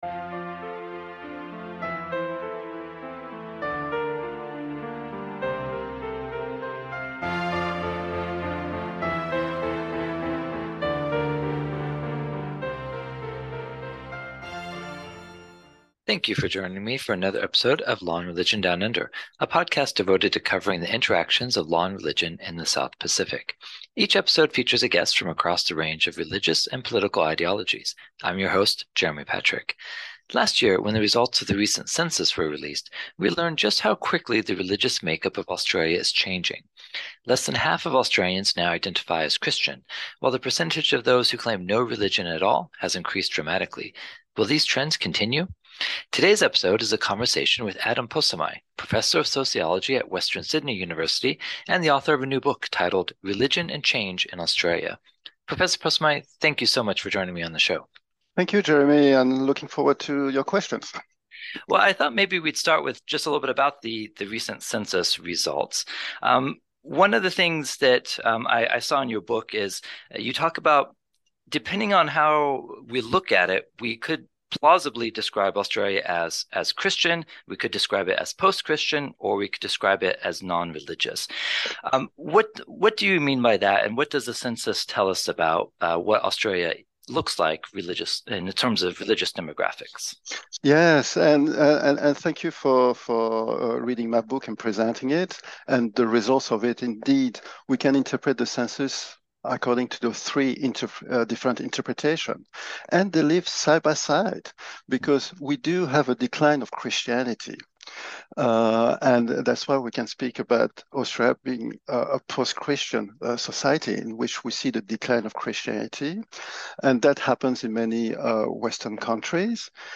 This episode features an interview